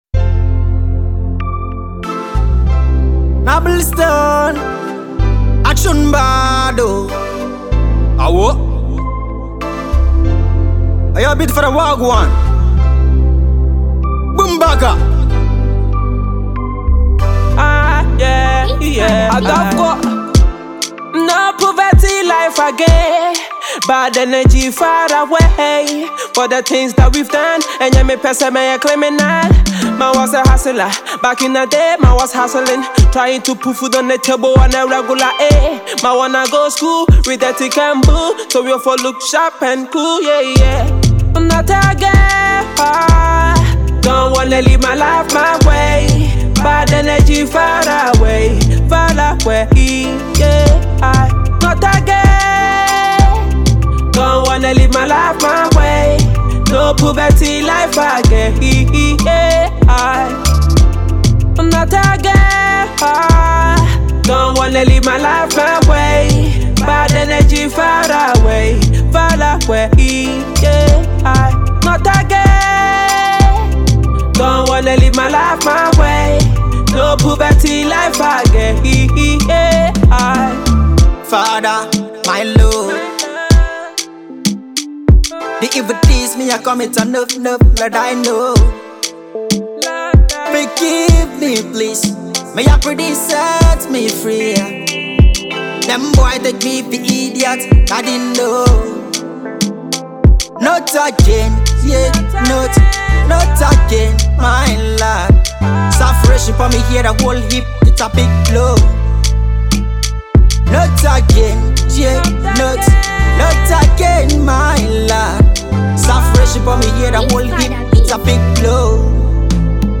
inspirational mind blowing track